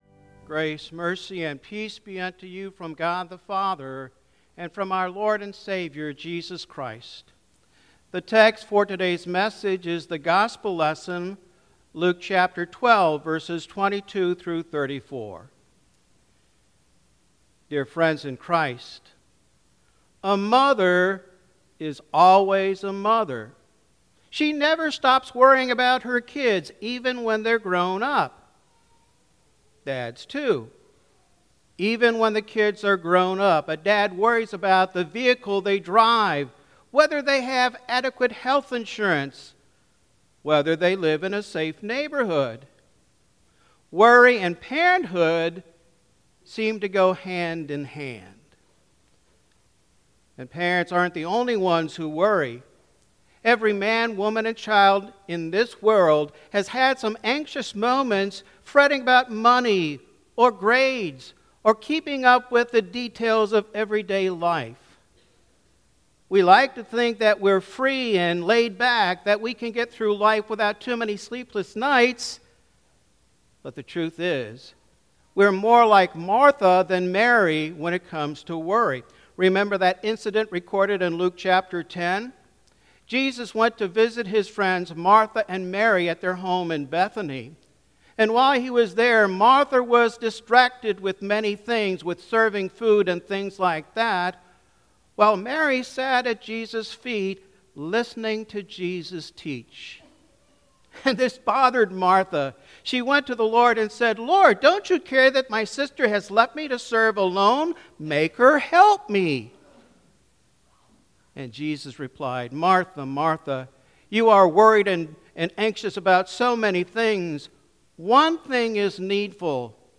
Pentecost